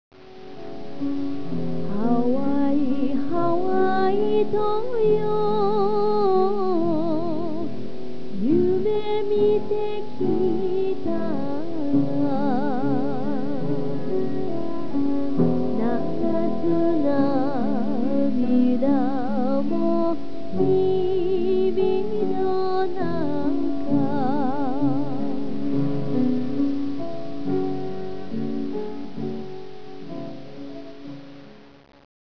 "Hole hole bushi" are the only surviving folk tunes describing life and work among immigrant laborers on Hawai`i's sugar plantations.